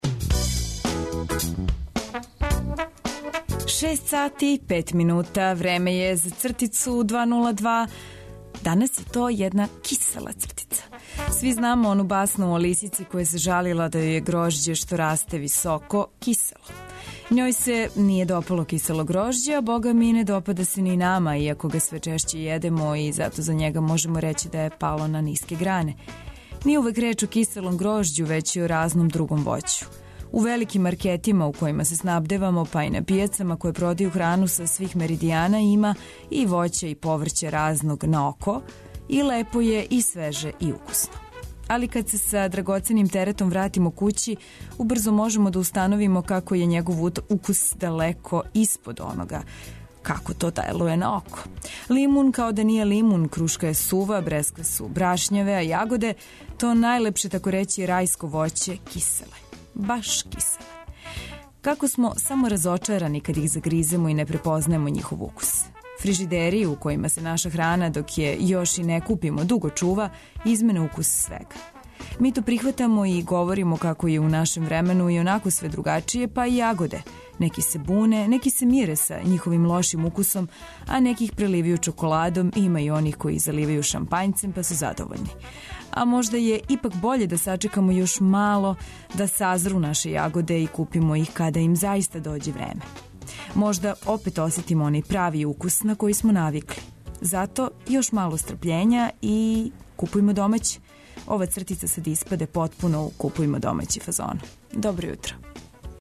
Поред свега наведеног, ту су и различити радијски прилози, добра музика и информације које је битно чути ујутру.